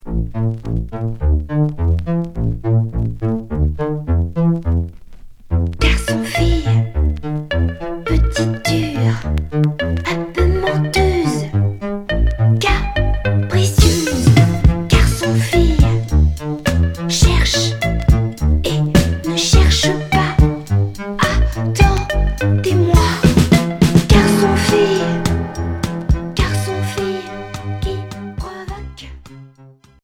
Minimal synth